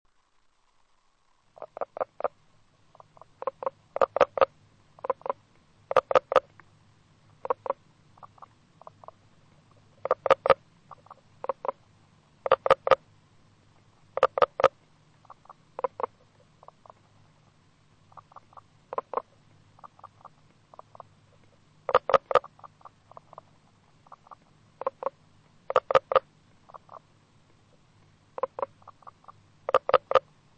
Tierstimme:
Ruf der Knoblauchkröte
Die Balzrufe werden nachts unter Wasser ausgestoßen und sind bereits wenige Meter vom Laichgewässer entfernt nicht mehr hörbar.
07 - Knoblauchkroete.mp3